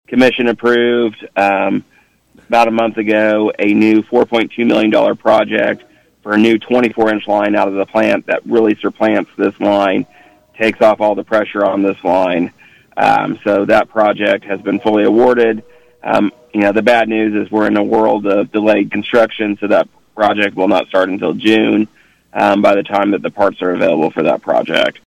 On KVOE’s 8:05 am newscast Tuesday, City Manager Trey Cocking said the city has seen the need and doing its best to meet it.